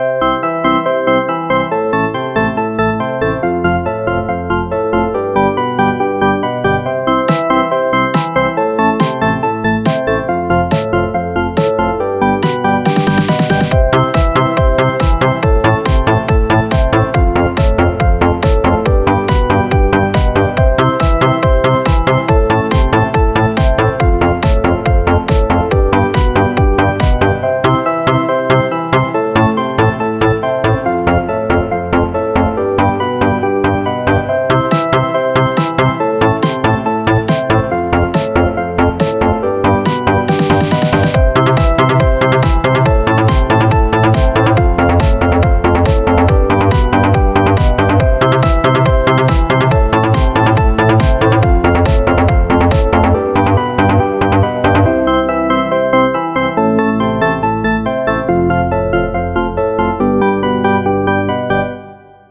Category 🎵 Music